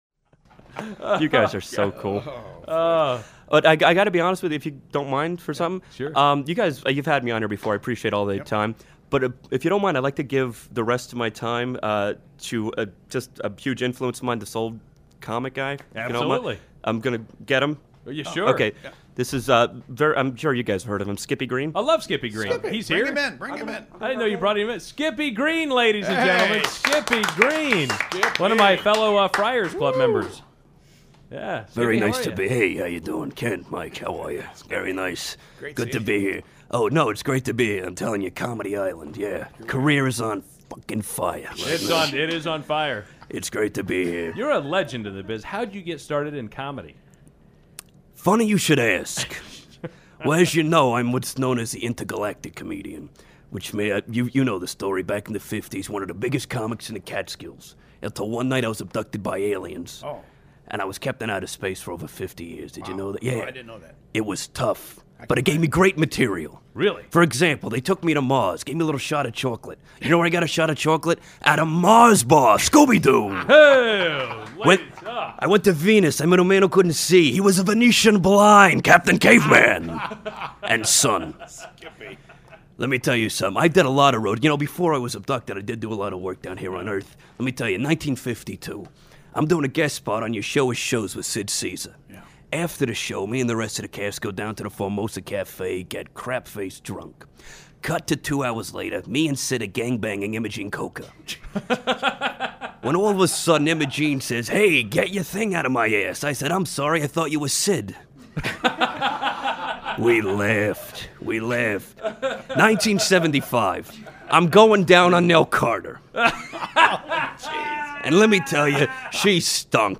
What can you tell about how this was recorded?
I edited some boring parts out, so I'm going to be putting it up on the site soon.